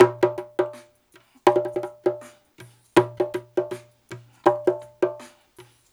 81-BONGO2.wav